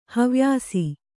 ♪ havyāsi